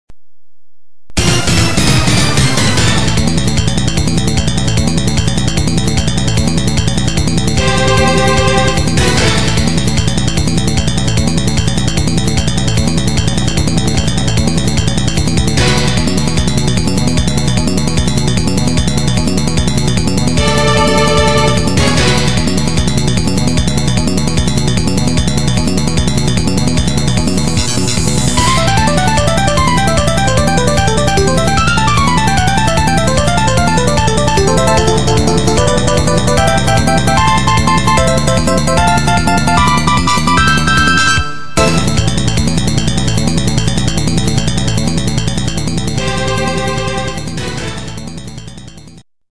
～～焦る系の曲～～